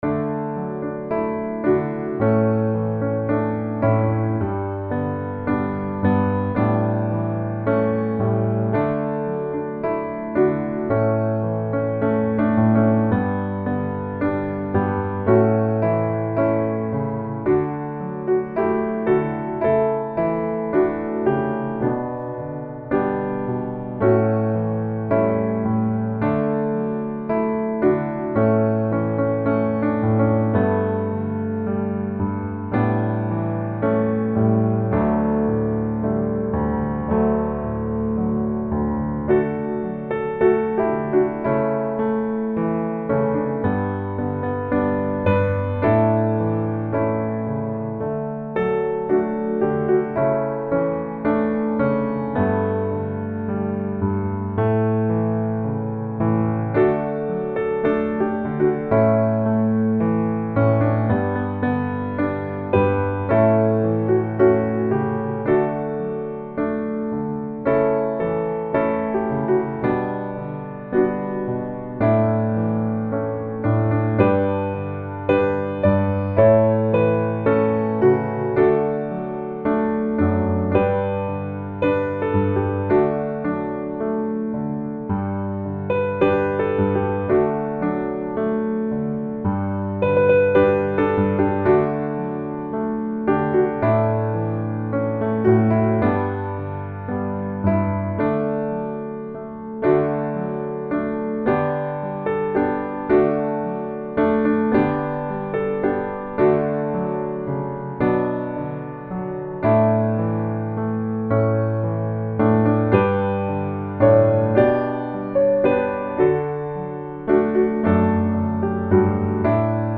(1B & 1C can be sung together)
D Majeur